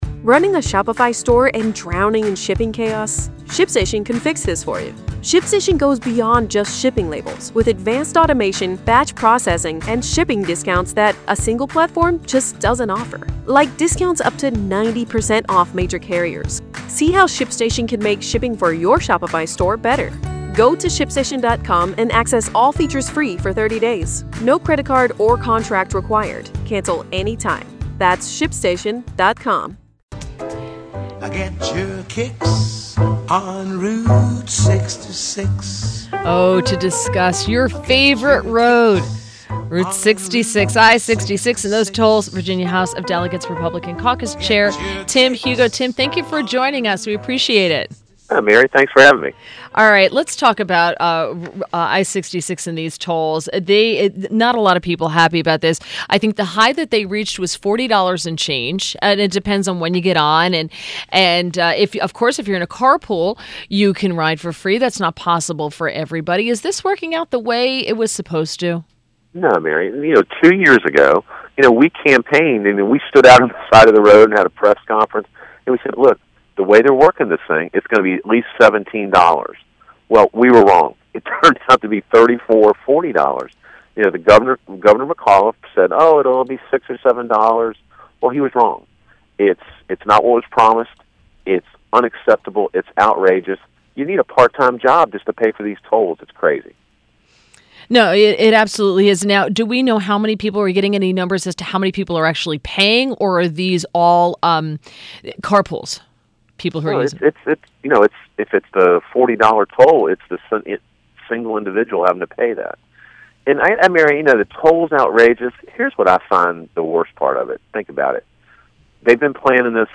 WMAL Interview - TIM HUGO - 12.6.17